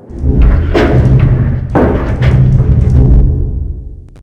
lockingon.ogg